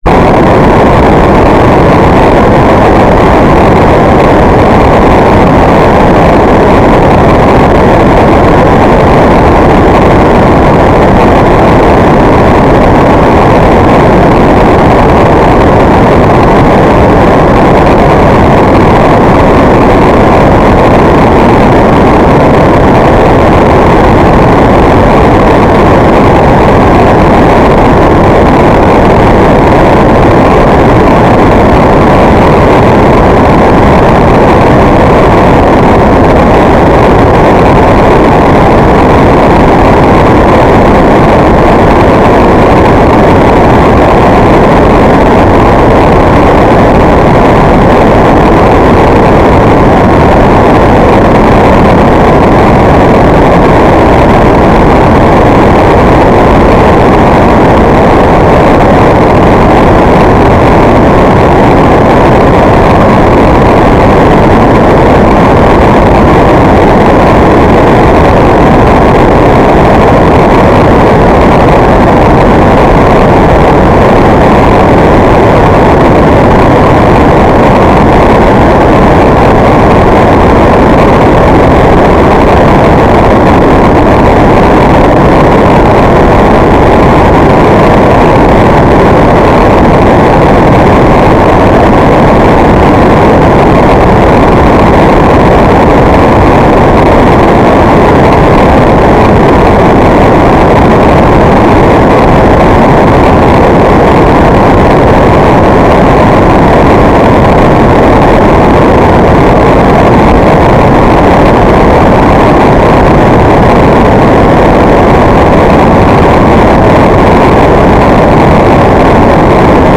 "transmitter_description": "Mode U - FSK1k2 AX.100",
"transmitter_mode": "FSK AX.100 Mode 5",